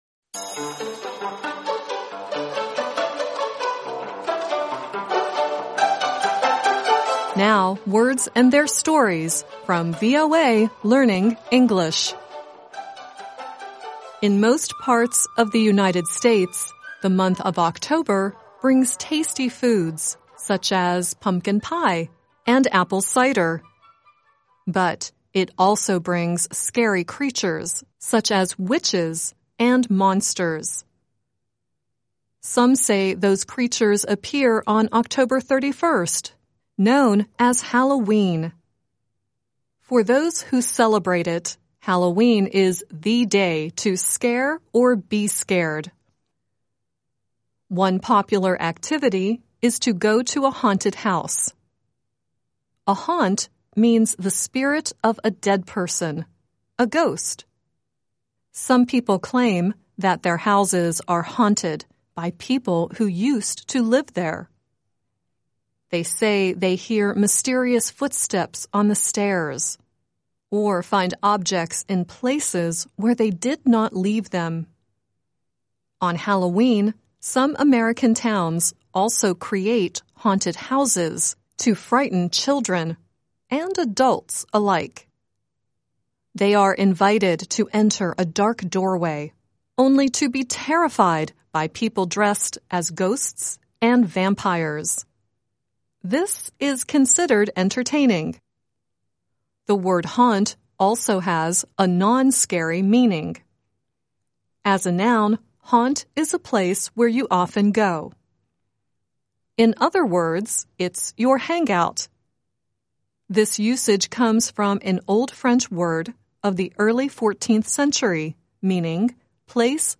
At the end of the program, Sade sings "Haunt Me" at the end.